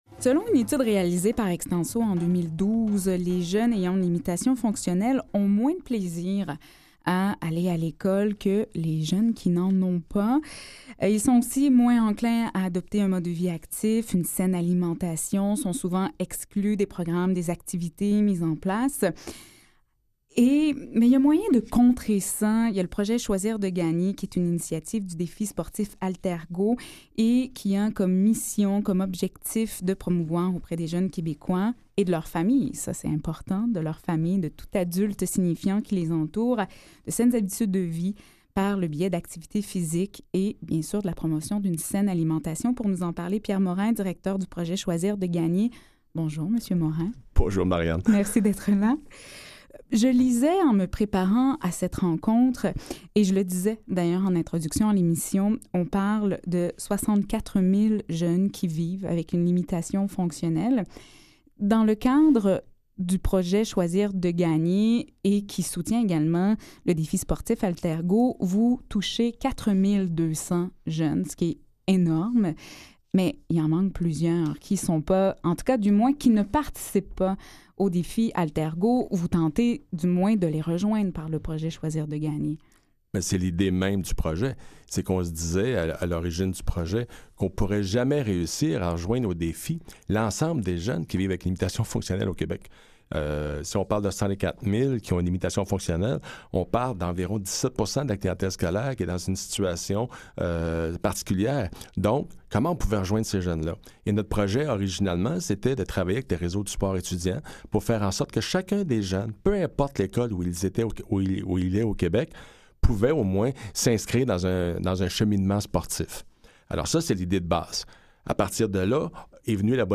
Pour en savoir plus ou pour aider votre enfant à développer de saines habitudes de vie, écoutez cette première chronique Choisir de Gagner.